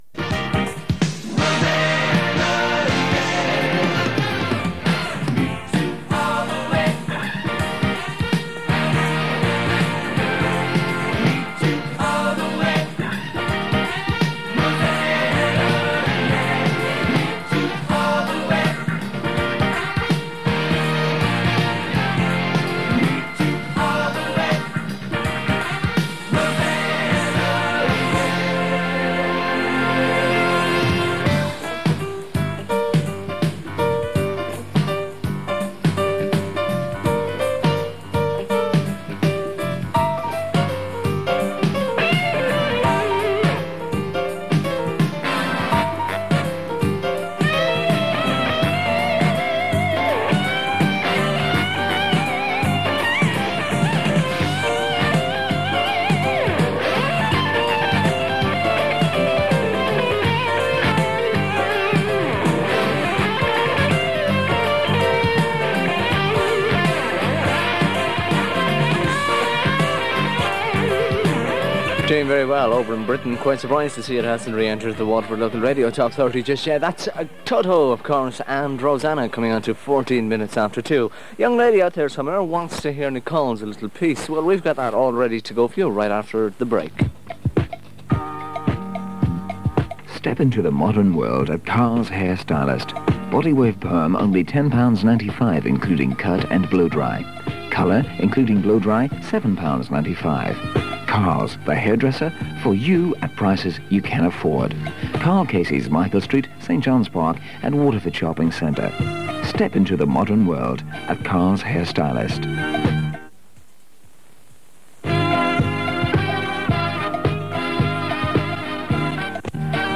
There are plenty of requests from listeners and adverts are heard for local businesses from Waterford City and beyond, including one for a WLR roadshow at a local hotel.
The station also broadcast on 1197 kHz AM, announcing 252 metres as reflected in some of the liners in this recording.